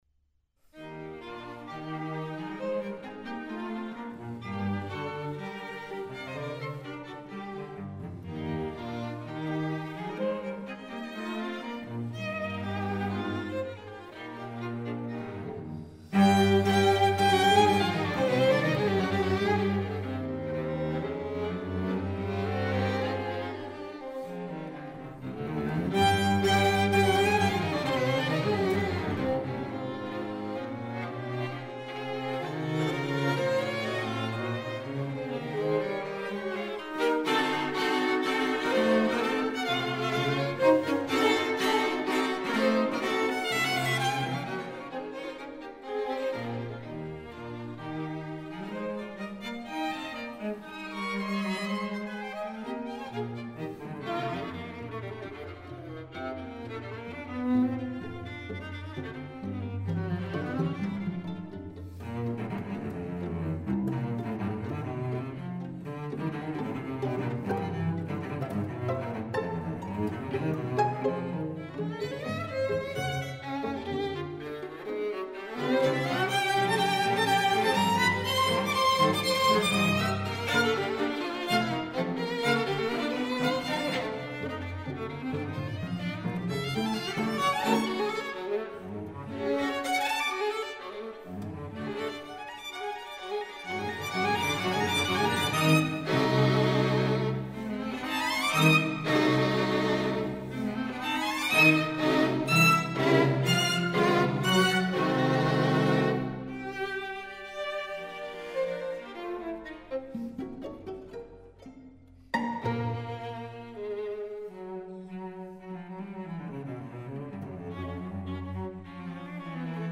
The ensemble has a special interest in Swiss chamber music.